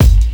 Tuned drums (G key) Free sound effects and audio clips
• Fresh Shiny Bass Drum G Key 577.wav
Royality free kick drum sound tuned to the G note. Loudest frequency: 508Hz
fresh-shiny-bass-drum-g-key-577-xw9.wav